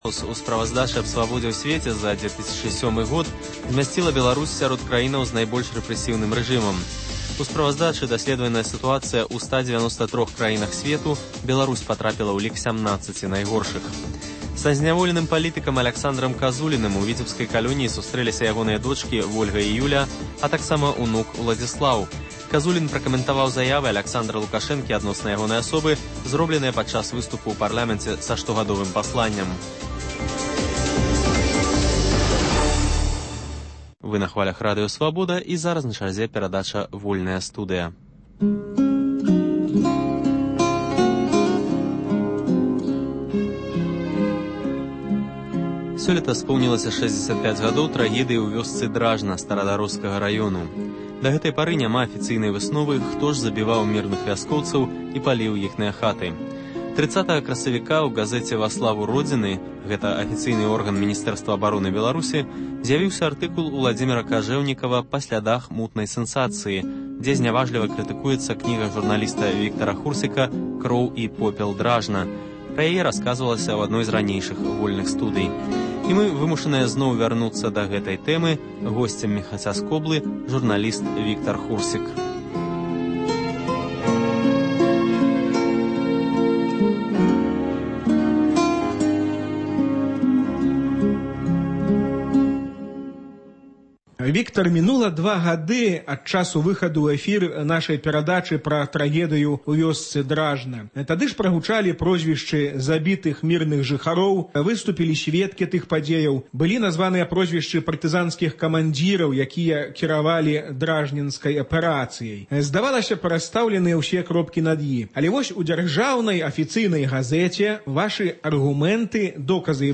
Сёлета споўнілася 65 гадоў трагедыі ў вёсцы Дражна Старадароскага раёну. Гутарка